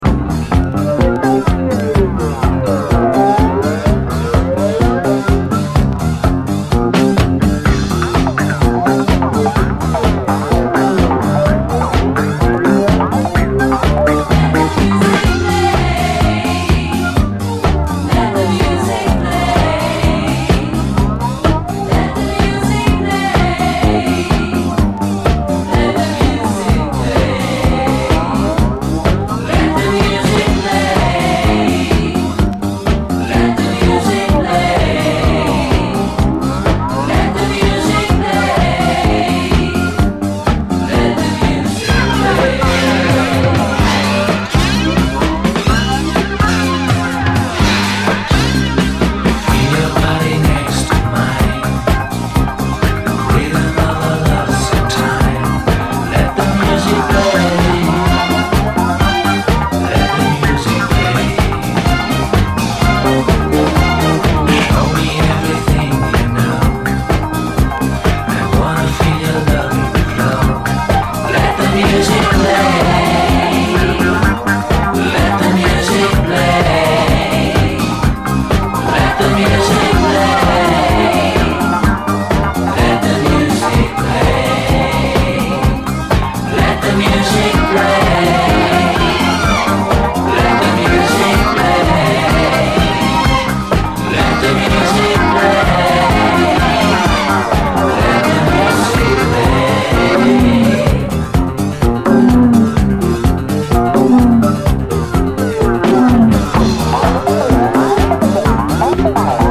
とにかくイイ曲だらけ！
（試聴ファイルは以前の録音のもの。